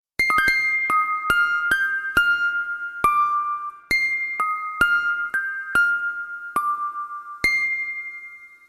Звуки сообщений
На данной странице вы можете прослушать онлайн короткие звуки уведомлений для sms на  телефон android, iPhone и приложения.